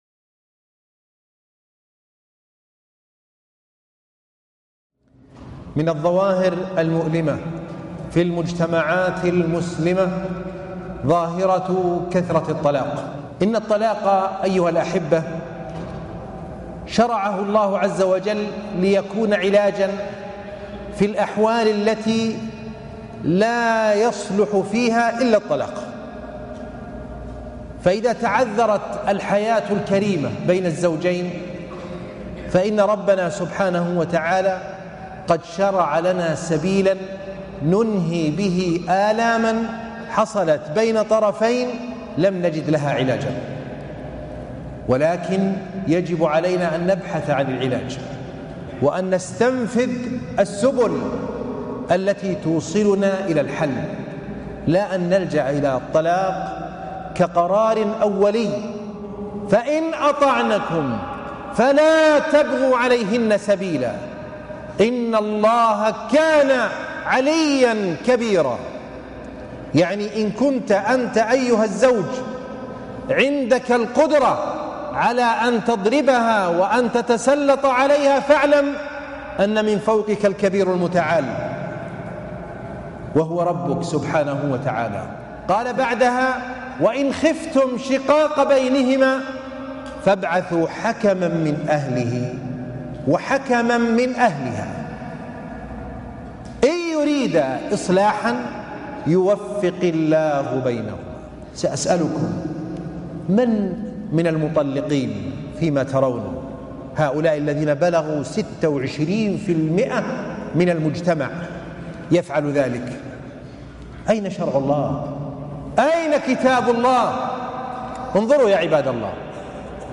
أسباب كثرة الطلاق - خطبة الجمعة